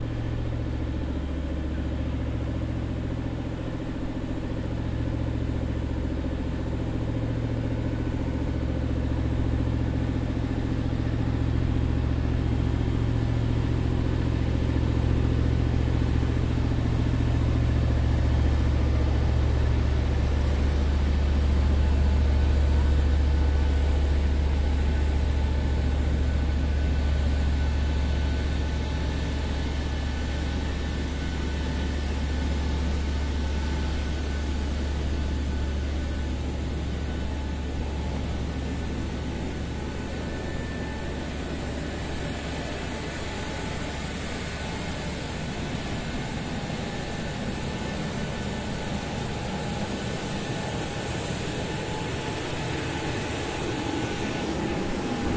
飞机1
描述：飞机在着陆前低空飞行，发动机处于低状态
Tag: 飞机 飞机 着陆 飞机 飞机